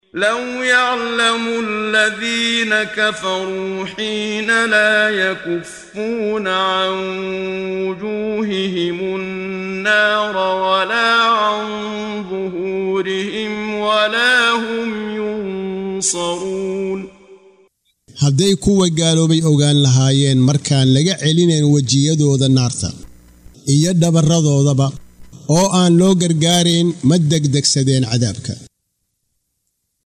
Waa Akhrin Codeed Af Soomaali ah ee Macaanida Suuradda Al-Anbiyấa ( Nabiyada ) oo u kala Qaybsan Aayado ahaan ayna la Socoto Akhrinta Qaariga Sheekh Muxammad Siddiiq Al-Manshaawi.